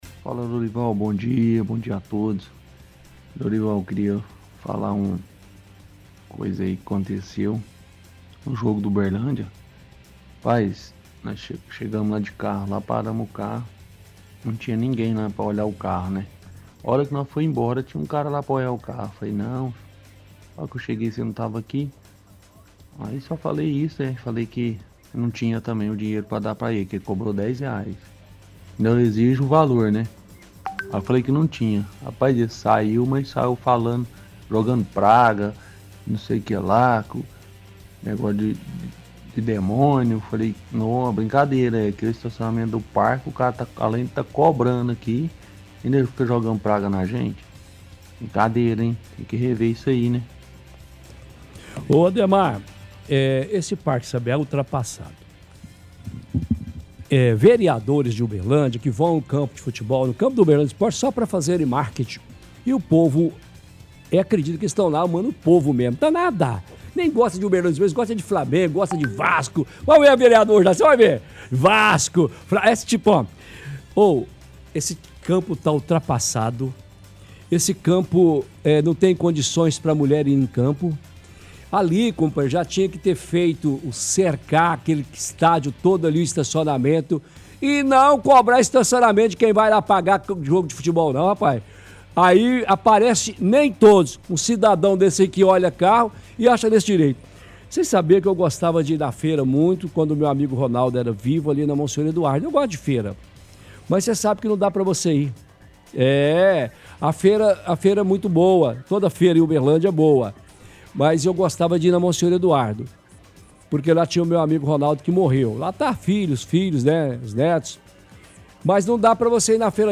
– Ouvinte questiona quando foi no ultimo jogo que teve no estádio Parque do sabiá, tinha pessoas cobrando por estacionamento.
– Ouvinte questiona a estrutura do estádio, fala que precisa de modernizar a estrutura do estádio.